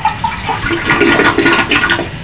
Hear the tragic sound of wasted tissue being flushed away.
toilet.wav